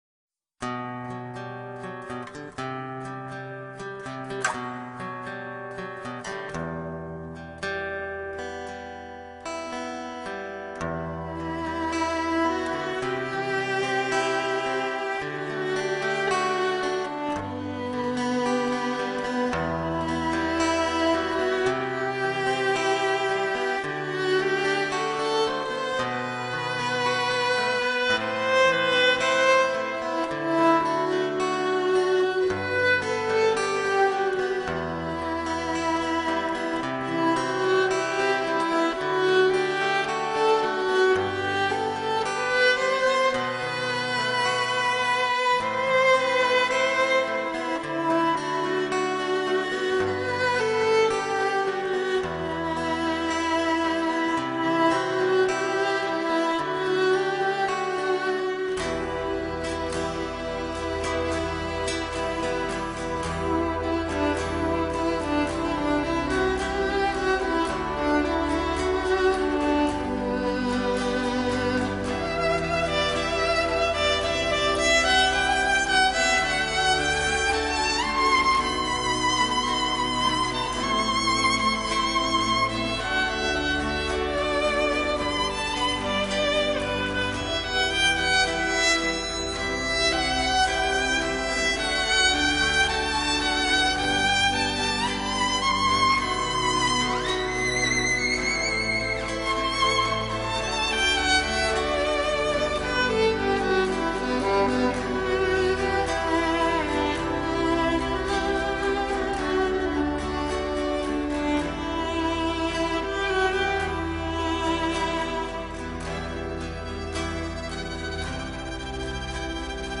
Tu-sei-la-mia-vita-Instrumental.mp3